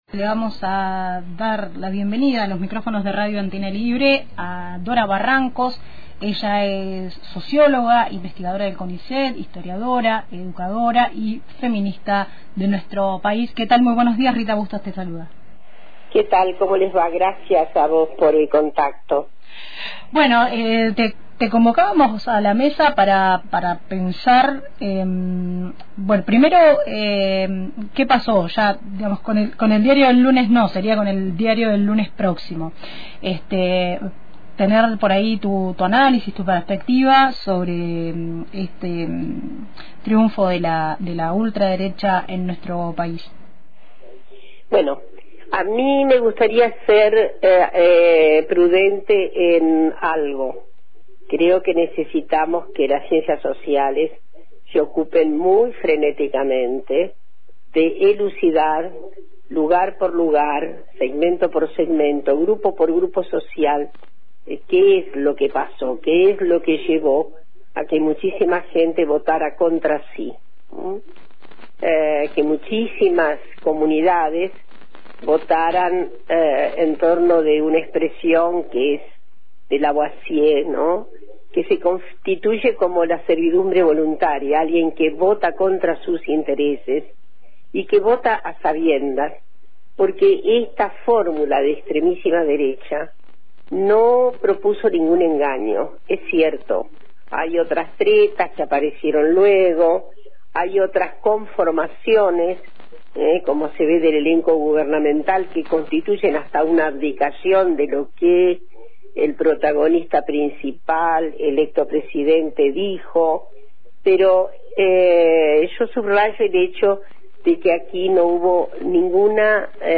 De cara a la asunción de Milei como presidente de la nación, conversamos con Dora Barrancos, socióloga, historiadora y e investigadora del CONICET.